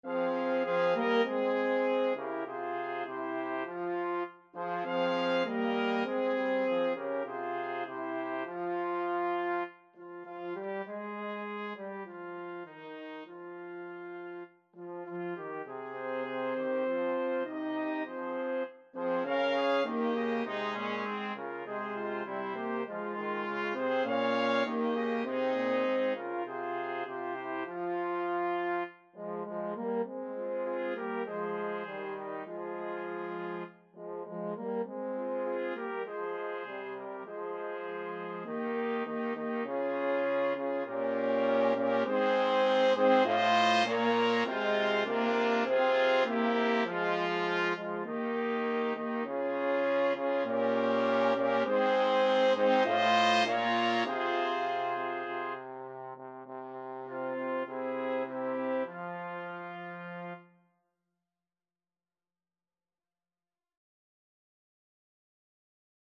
Trumpet 1Trumpet 2French HornTrombone
Moderato
2/4 (View more 2/4 Music)
Classical (View more Classical Brass Quartet Music)